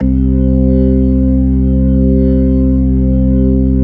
Index of /90_sSampleCDs/AKAI S-Series CD-ROM Sound Library VOL-8/SET#5 ORGAN
HAMMOND   5.wav